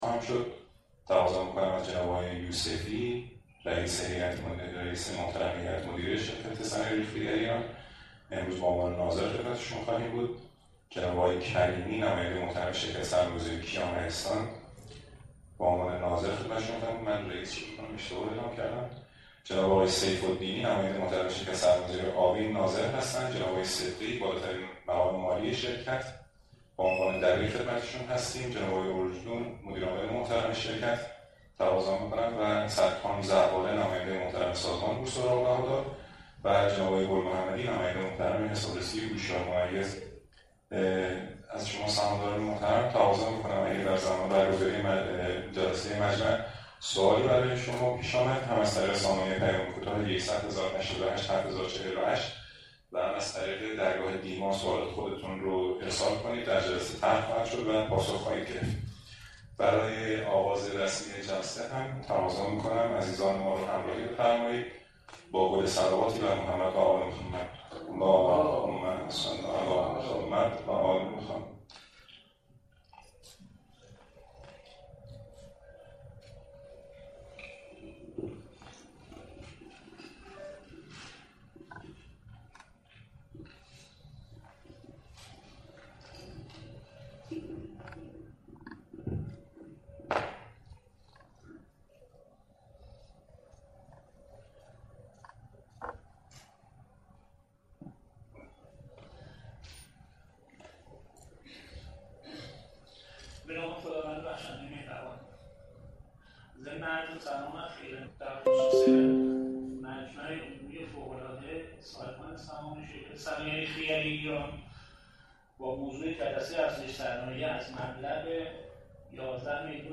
مجمع عمومی فوق العاده شرکت صنایع ریخته گری ایران - نماد: خریخت